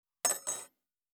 245,食器にスプーンを置く,ガラスがこすれあう擦れ合う音,グラス,コップ,工具,小物,雑貨,コトン,
コップ